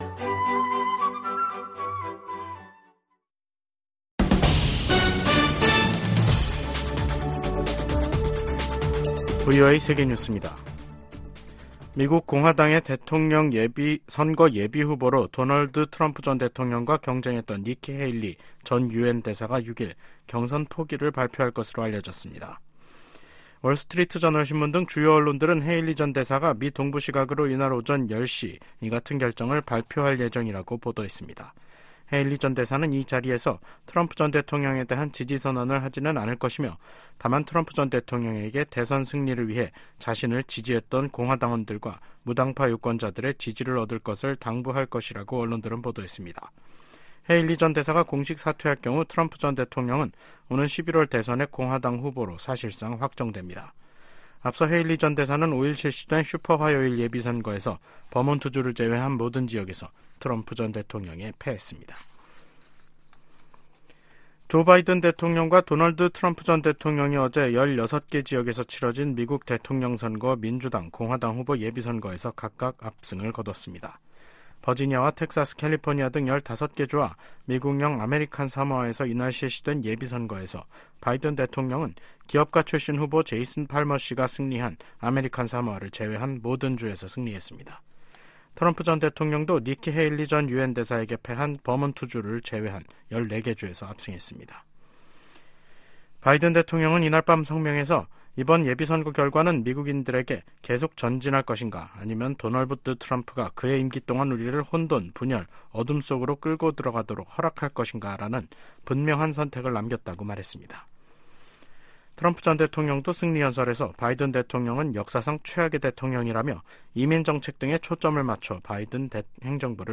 세계 뉴스와 함께 미국의 모든 것을 소개하는 '생방송 여기는 워싱턴입니다', 2024년 3월 6일 저녁 방송입니다. '지구촌 오늘'에서는 국제형사재판소(ICC)가 러시아군 고위 장성 2명 체포영장을 발부한 소식 전해드리고, '아메리카 나우'에서는 조 바이든 대통령과 도널드 트럼프 전 대통령이 '슈퍼화요일' 민주·공화당 경선에서 각각 압승한 이야기 살펴보겠습니다.